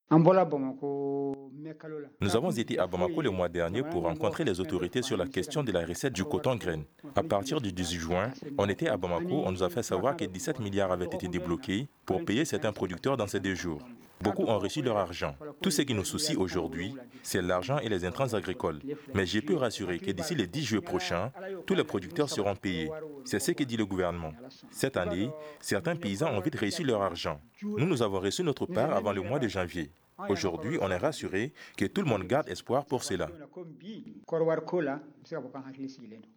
La nouvelle a été annoncée par le ministre de l’Agriculture Daniel Siméon Kéléma lors d’une conférence de presse mercredi dernier 25 juin.